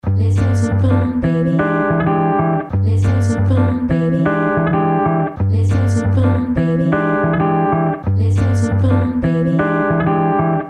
描述：吉他加女声说 "让我们找点乐子宝贝quot。
Tag: 135 bpm Fusion Loops Groove Loops 1.79 MB wav Key : Unknown